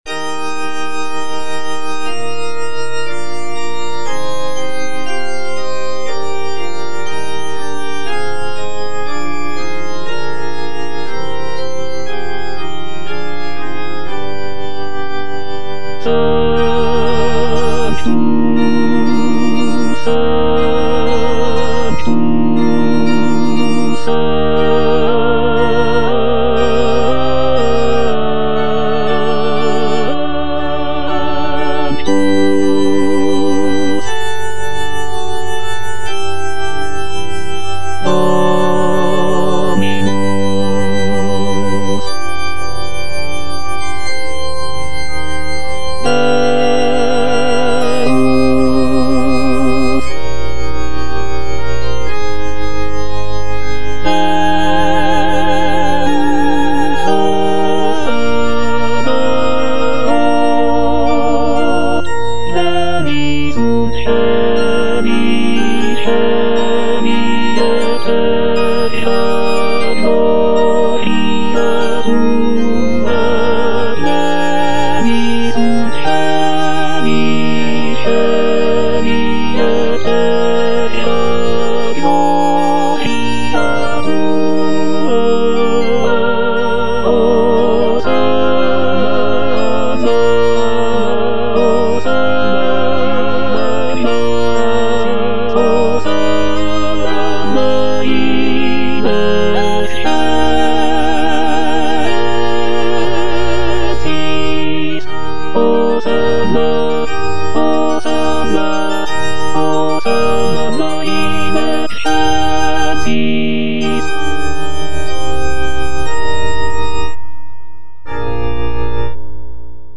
J.G. RHEINBERGER - MASS IN C OP. 169 Sanctus - Tenor (Emphasised voice and other voices) Ads stop: auto-stop
This composition is known for its rich textures, expressive melodies, and intricate interplay between the vocal and instrumental sections, making it a notable contribution to the genre of sacred choral music.